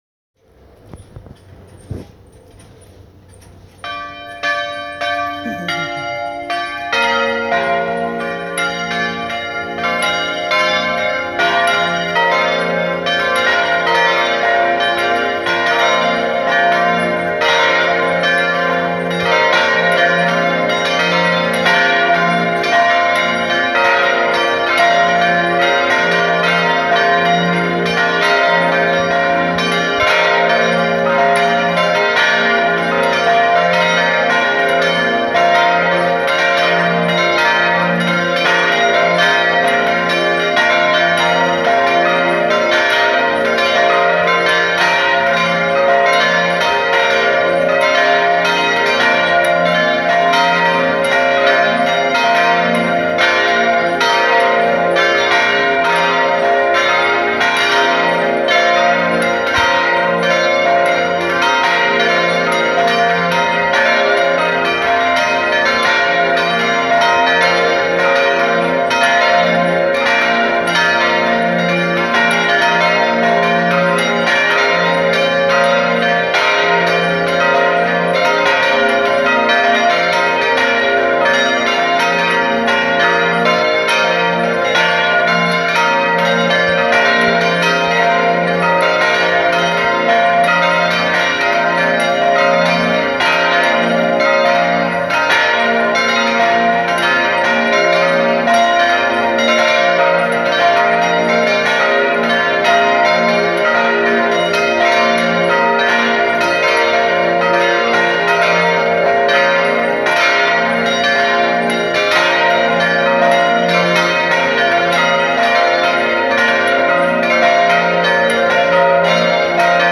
J’aime le son de cloches qui sonnent. Je décide donc d’arriver en retard à la prière du matin pour pouvoir les enregistrer… Elles sont cinq.
Elles revolent joyeusement pendant une bonne dizaine de minutes et je suspecte qu’elles servent de réveil-matin à des ados qui se dirigent vers l’église cinq minutes après leur début, les cheveux encore tout ébouriffés 😁
Taizé-cloches.m4a